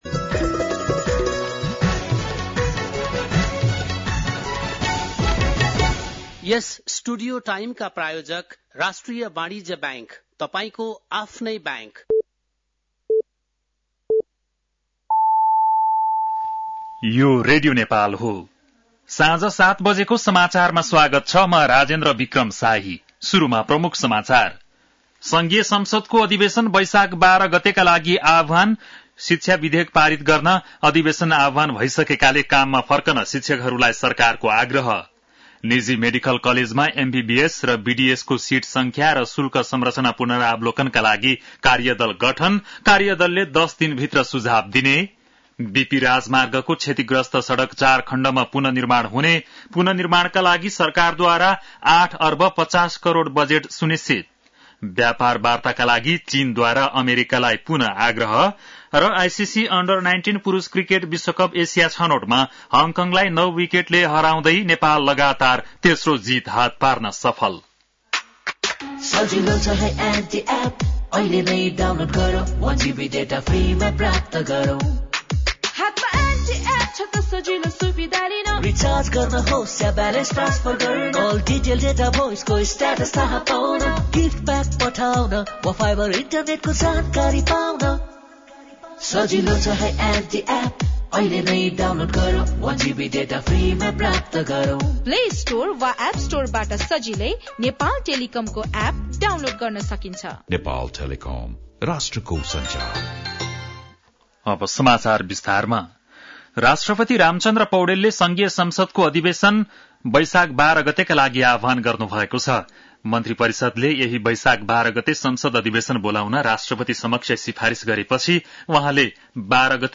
An online outlet of Nepal's national radio broadcaster
बेलुकी ७ बजेको नेपाली समाचार : ३ वैशाख , २०८२
7-pm-nepali-news.mp3